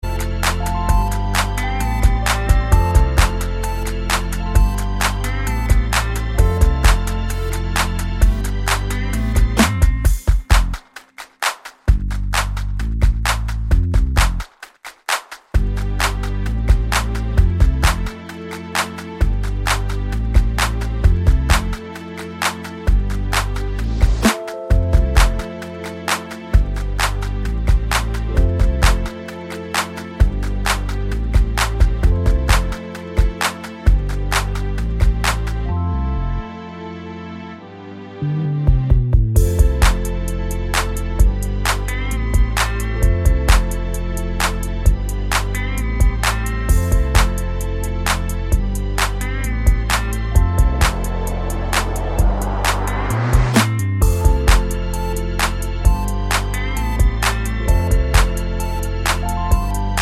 no Backing Vocals R'n'B / Hip Hop 4:12 Buy £1.50